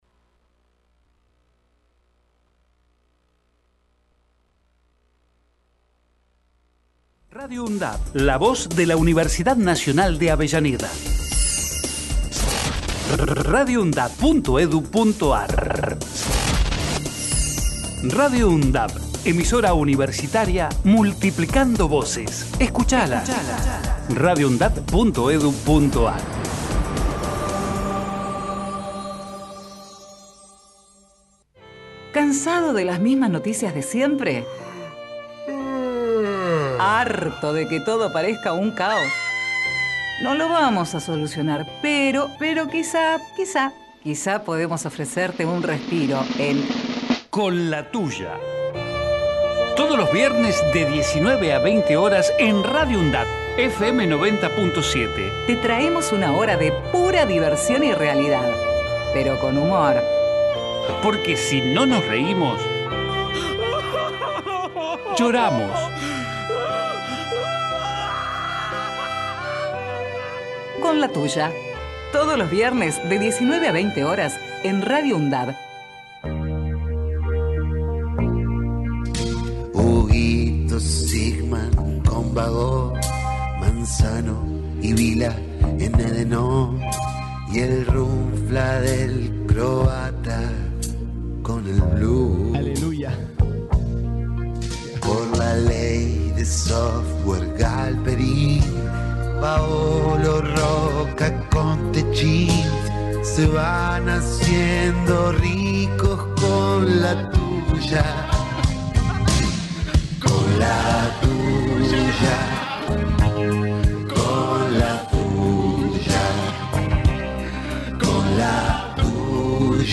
Cuenta también con una columna itinerante, con entrevistas e invitados especiales que serán parte de este programa que sale los viernes de 19 a 20.